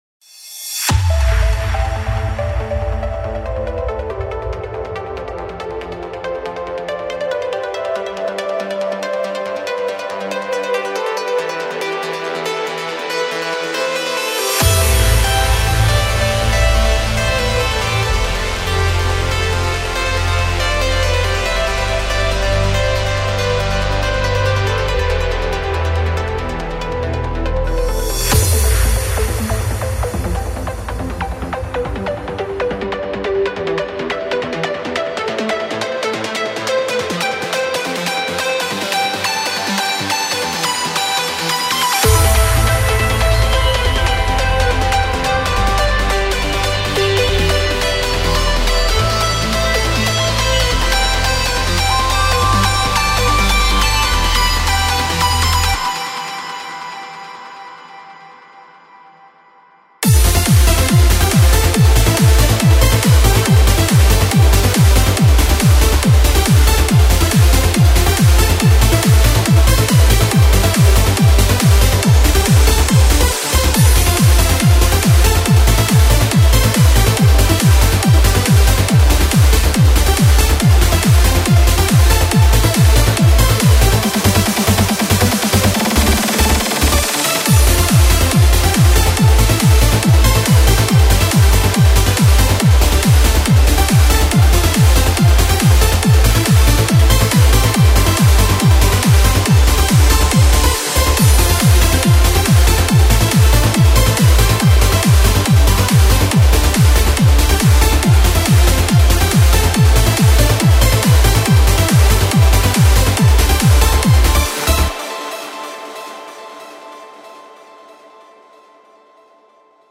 Psy-Trance Trance Uplifting Trance